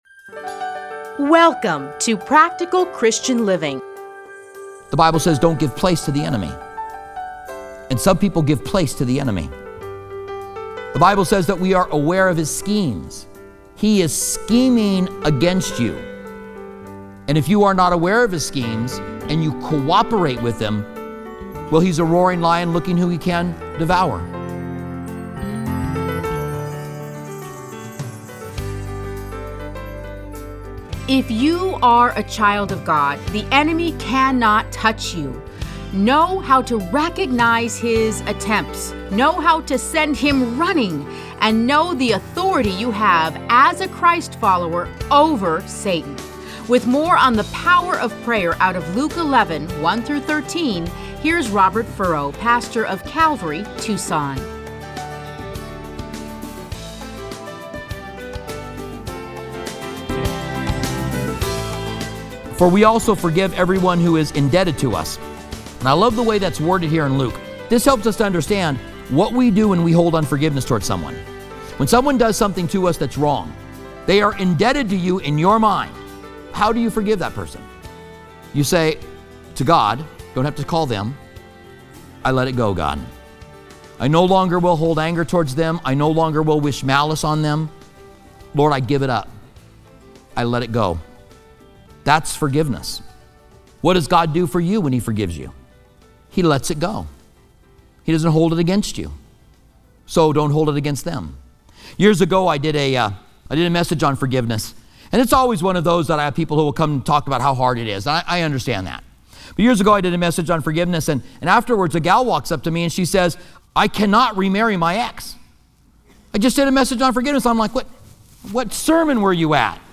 Listen to a teaching from Luke Luke 11:1-13.